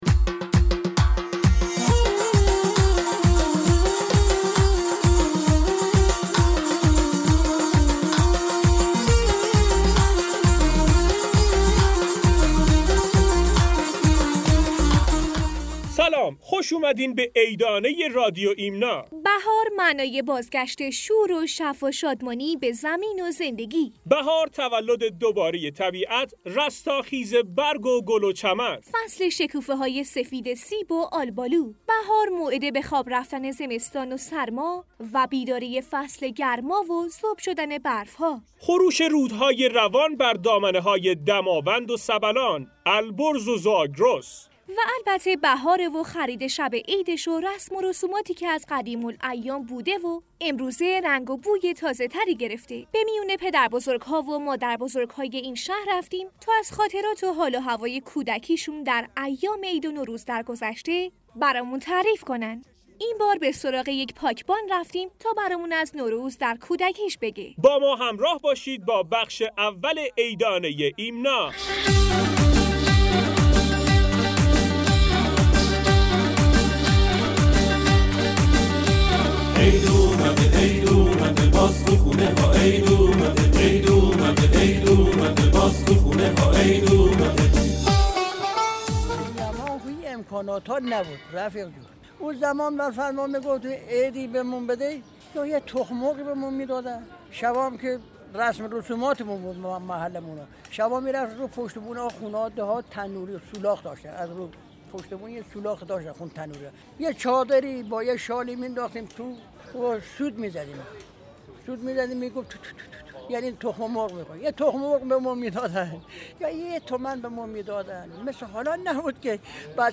در این قسمت به سراغ یک پاکبان رفتیم تا از خاطراتش برای ما بگوید.